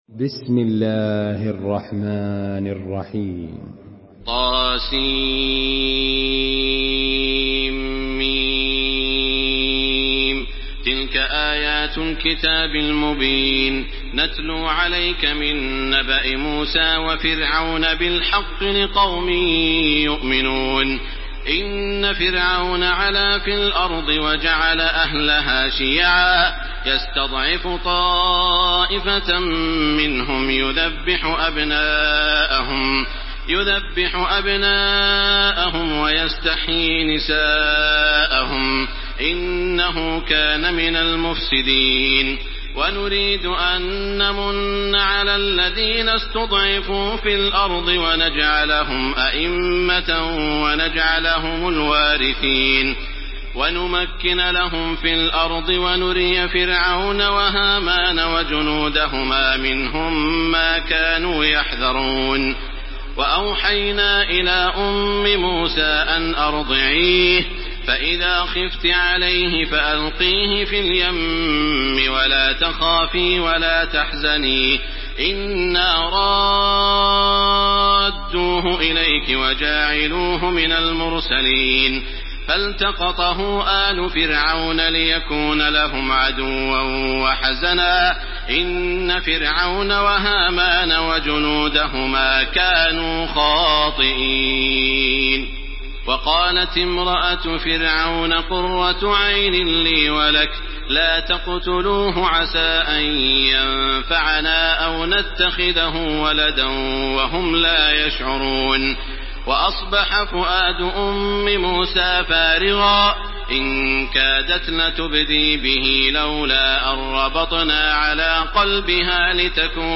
سورة القصص MP3 بصوت تراويح الحرم المكي 1429 برواية حفص
مرتل